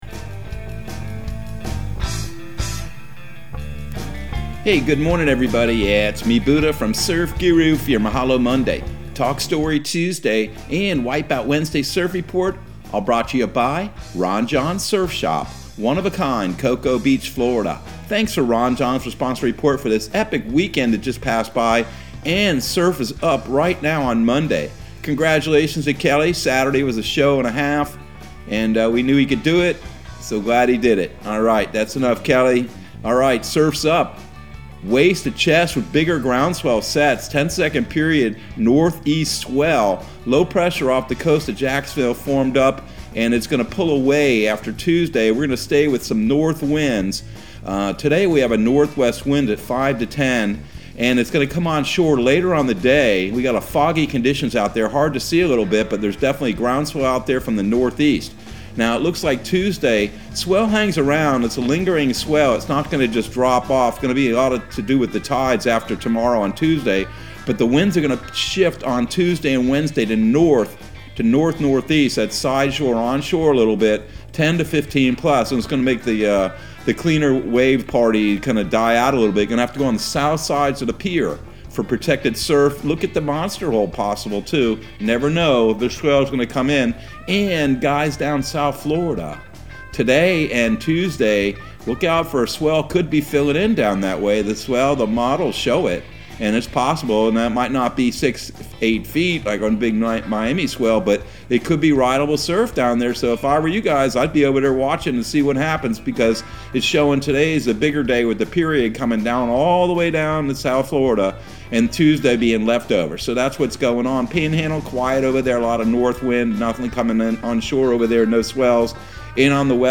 Surf Guru Surf Report and Forecast 02/07/2022 Audio surf report and surf forecast on February 07 for Central Florida and the Southeast.